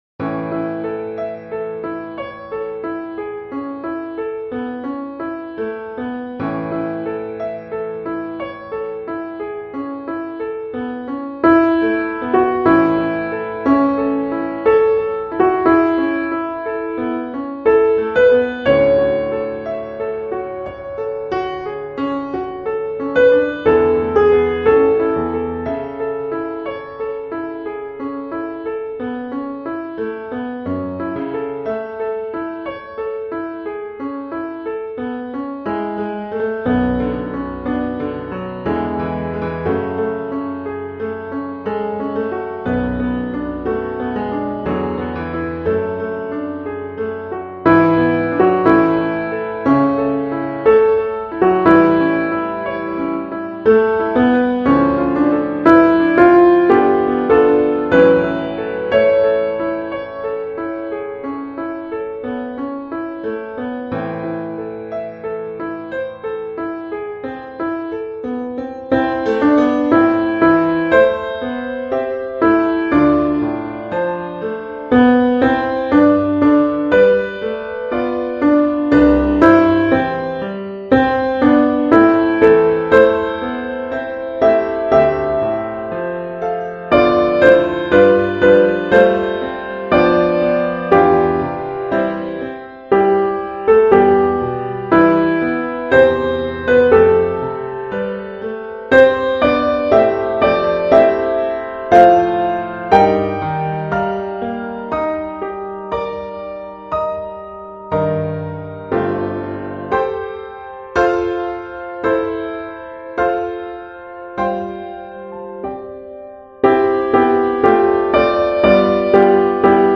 Let Me Hide Myself in Thee – Soprano
Let-Me-Hide-Myself-in-Thee-Soprano.mp3